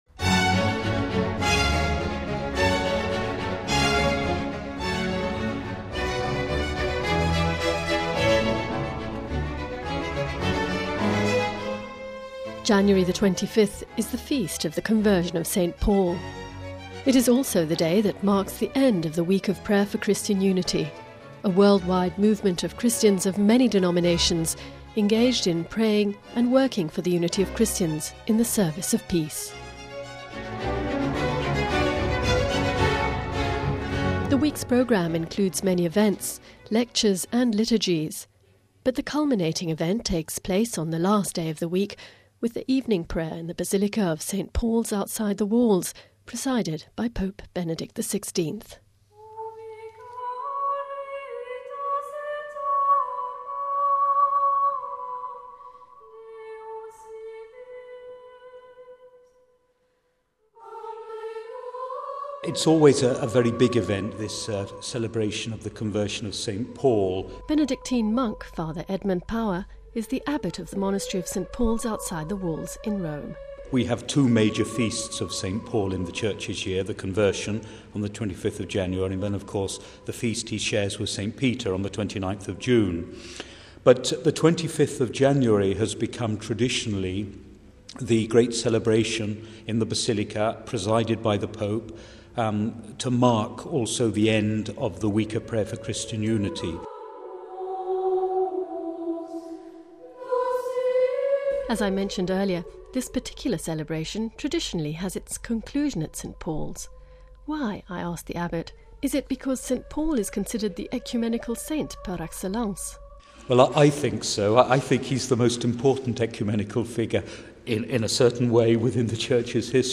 Home Archivio 2010-01-25 16:43:47 PRAYING FOR UNITY Pope Benedict XVIth celebrates Vespers at the close of the Week of Prayer for Christian Unity...